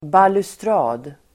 Uttal: [balustr'a:d]